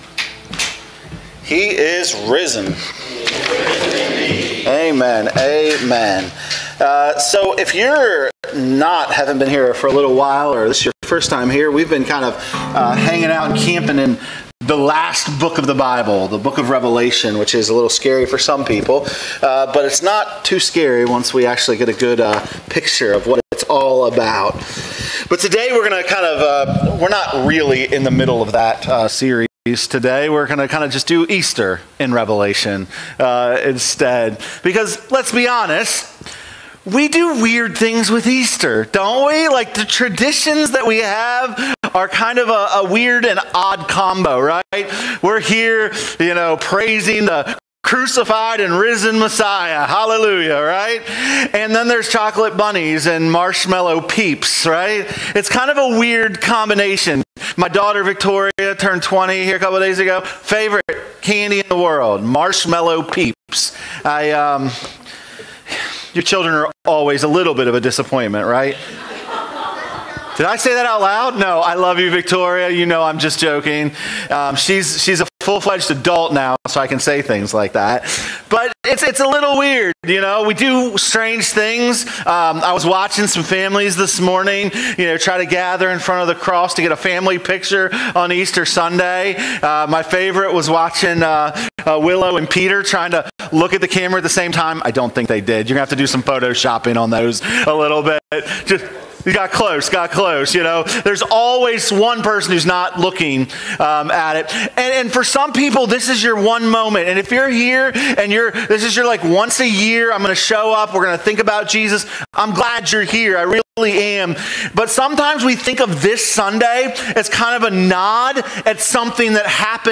All Sermons , Revealed Book Revelation Watch Listen Save Easter isn’t just about an event in the past—it’s about a reigning Christ in the present and a future hope that is already breaking into our world.